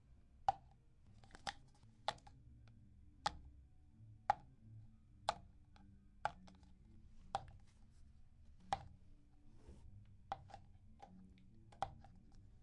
桌子上的声音
描述：Es el sonido de una taza cuando se lo coloca en la mesa